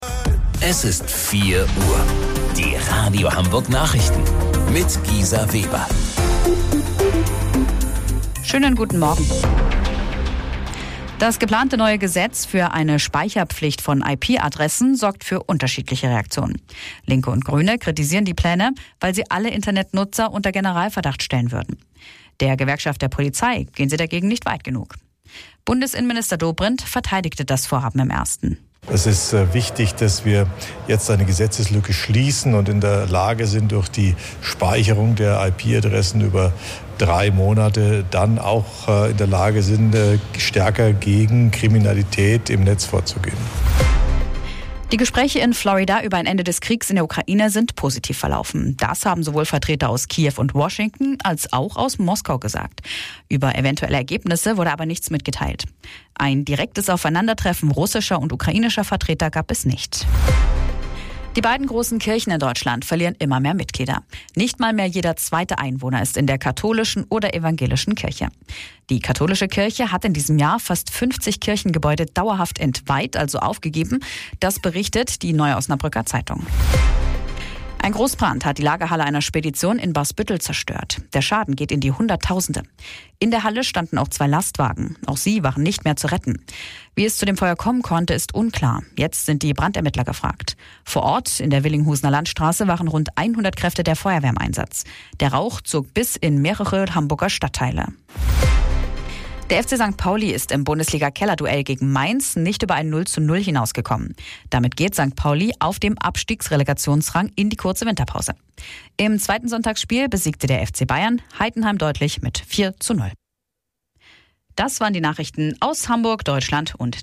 Radio Hamburg Nachrichten vom 22.12.2025 um 04 Uhr